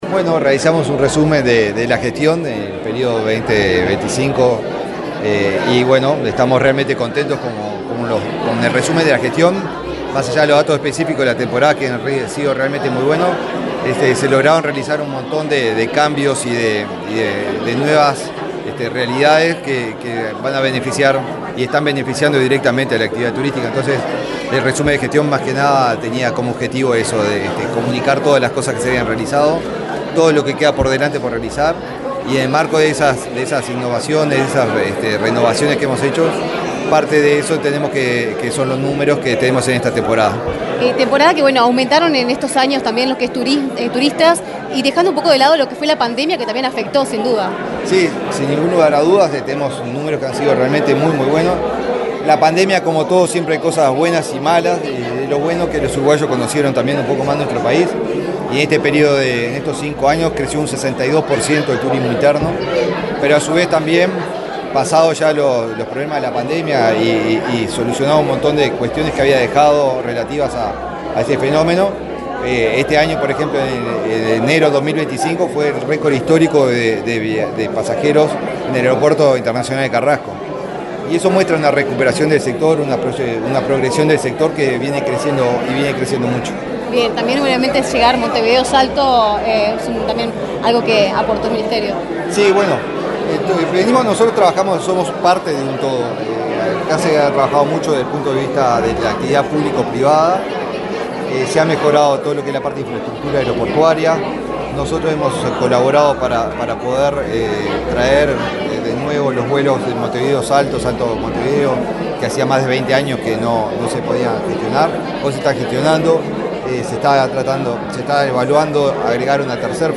Declaraciones del ministro de Turismo, Eduardo Sanguinetti
Este miércoles 26 en Montevideo, el ministro de Turismo, Eduardo Sanguinetti, dialogó con la prensa, luego de presentar el balance de gestión del